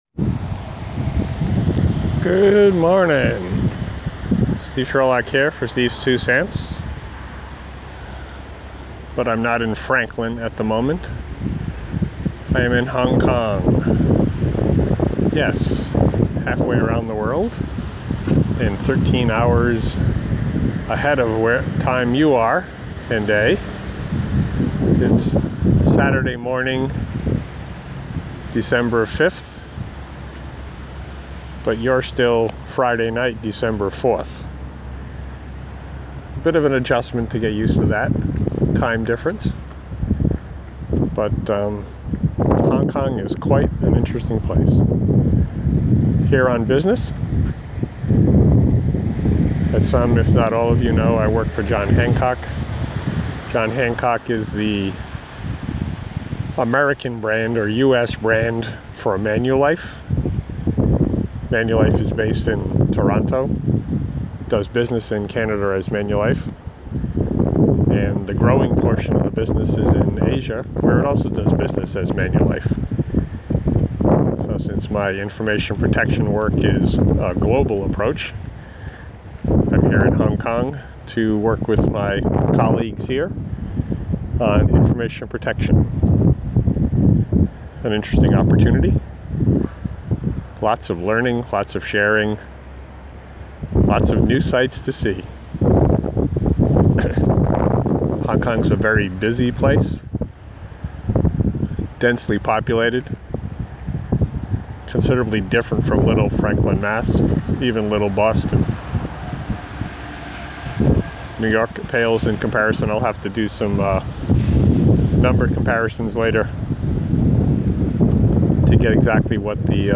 reflections on the day and time while half way around the world in Hong Kong